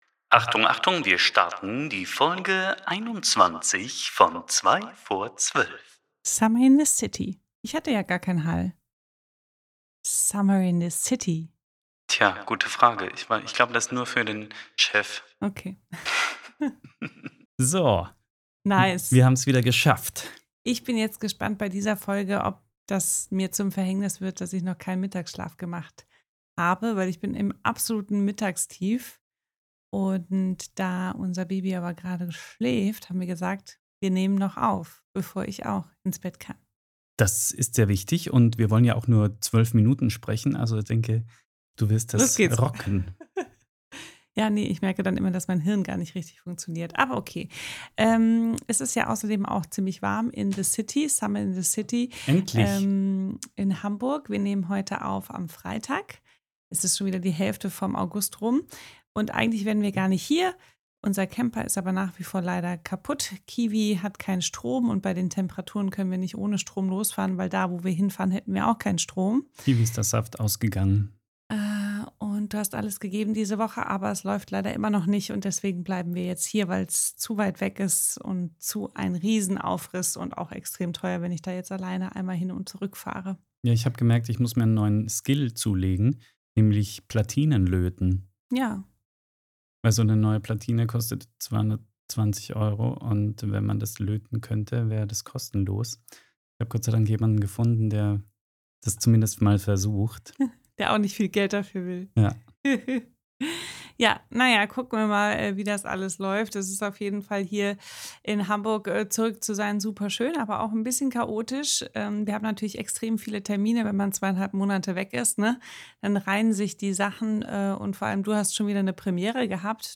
Kurzinterview Prof. Dr. Claudia Kemfert